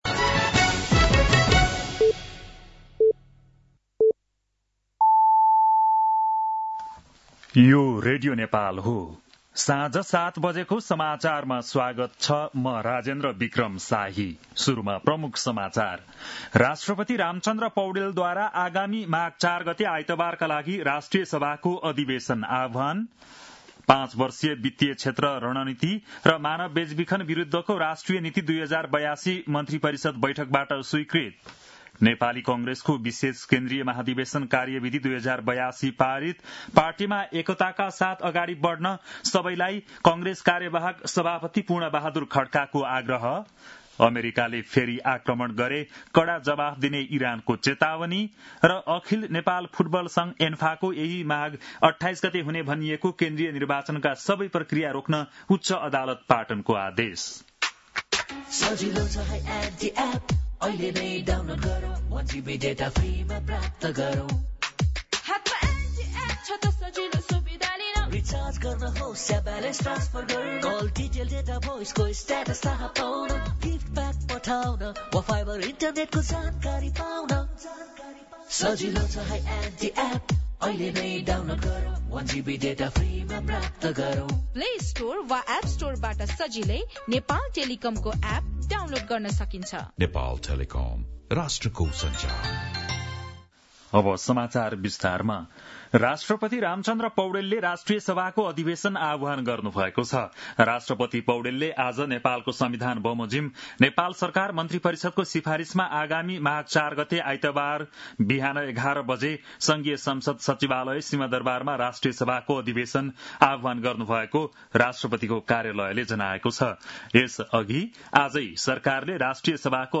बेलुकी ७ बजेको नेपाली समाचार : २८ पुष , २०८२
7-pm-nepali-news-9-28.mp3